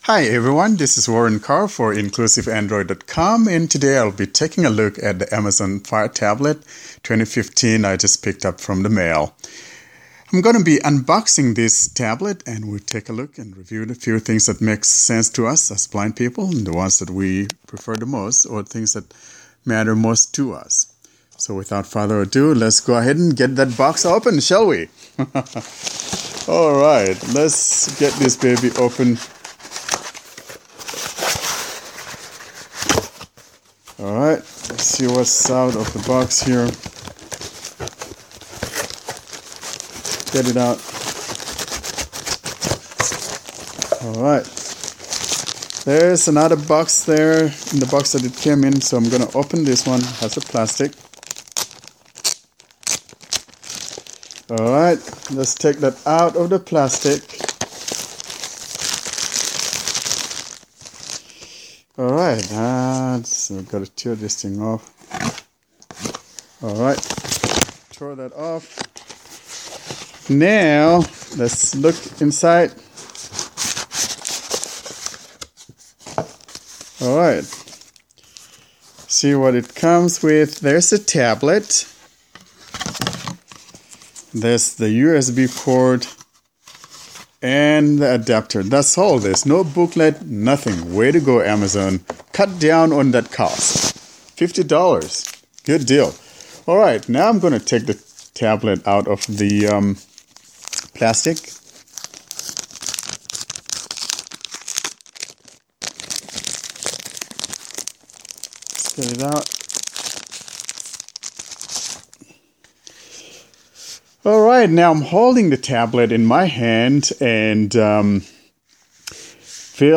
Unboxing Amazon_Fire_Tablet2015.mp3